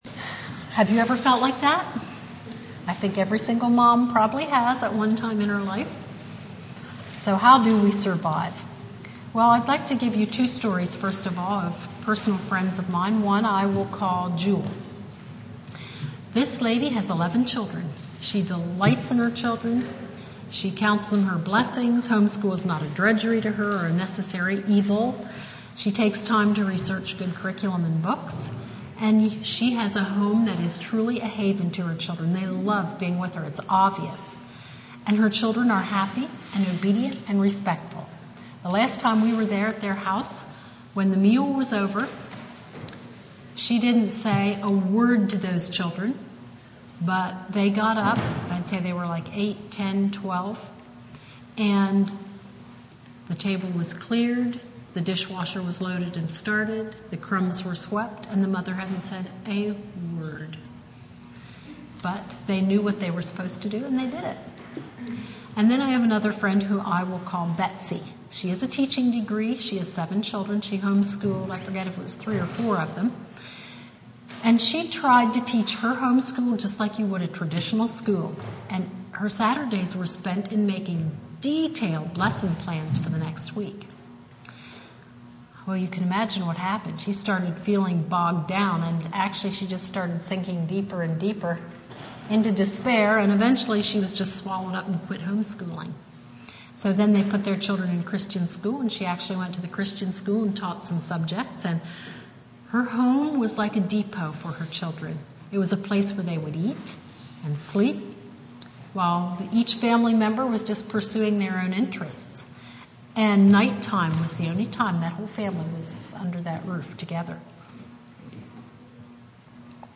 Home School Conference Service Type